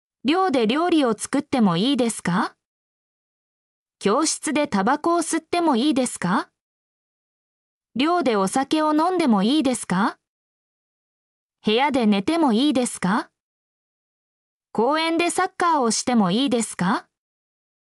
mp3-output-ttsfreedotcom-27_s4b5zBJE.mp3